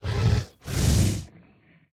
Minecraft Version Minecraft Version 25w18a Latest Release | Latest Snapshot 25w18a / assets / minecraft / sounds / mob / polarbear / idle2.ogg Compare With Compare With Latest Release | Latest Snapshot